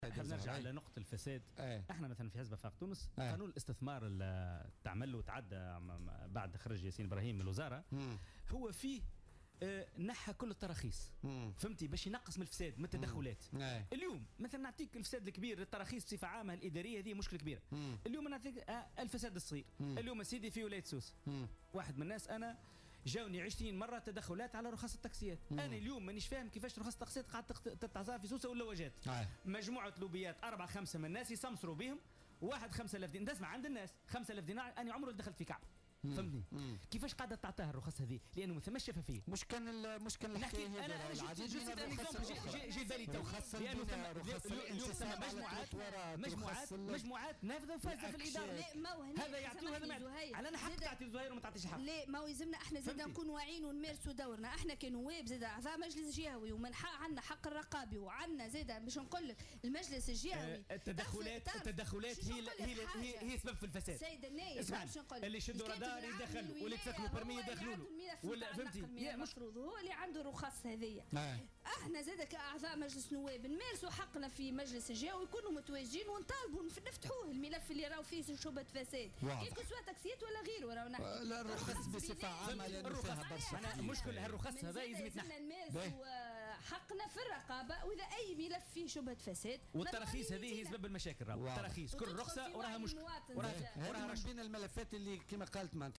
قال النائب عن افاق تونس حافظ الزواري ضيف بولتيكا اليوم الإثنين إن حزب افاق تونس ألغى كل التراخيص من خلال قانون الإستثمار لإنهاء الفساد والتدخلات.